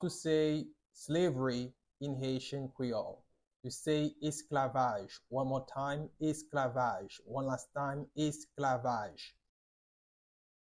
Pronunciation:
29.How-to-say-Slavery-in-Haitian-Creole-–-Esklavaj-with-pronunciation.mp3